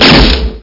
Amiga 8-bit Sampled Voice
handgun.mp3